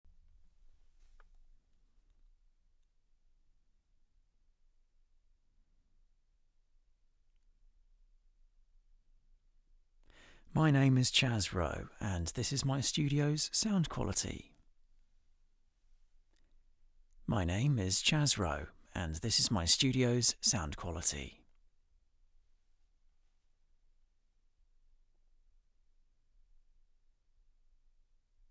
Commercial
Male
TEENS, 20s, 30s
British English (Native)
Approachable, Authoritative, Bright, Children, Confident, Conversational, Cool, Energetic, Engaging, Friendly, Natural, Reassuring, Soft, Upbeat, Warm, Young
Microphone: Sontronics Orpheus